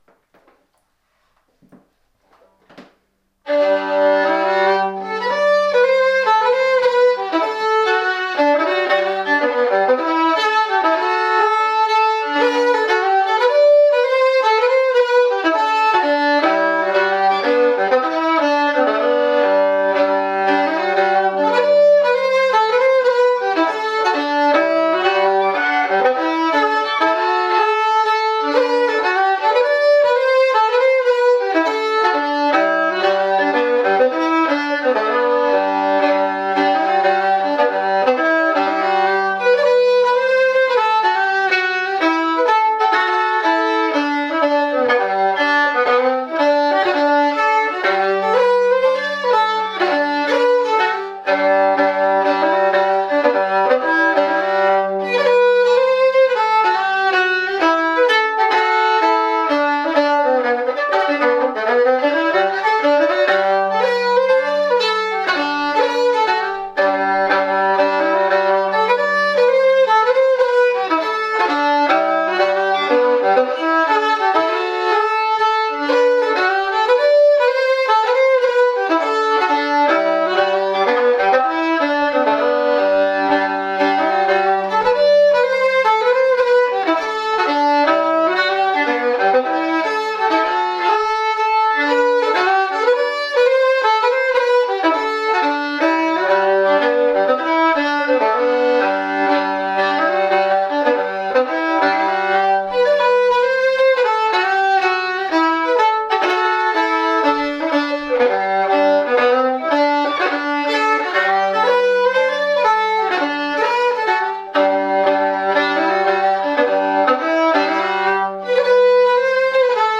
4/4 Reel  [D]
MSR-NW-Scottish-Fiddlers.mp3